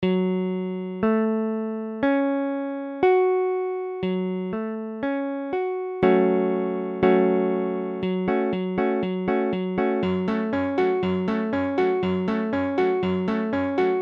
Gbm : accord de Sol b�mol mineur
Tempo : 1/4=60
Forme fondamentale : tonique quinte octave tierce mineure Pour les accords de trois notes, on redouble souvent la tonique � l'octave.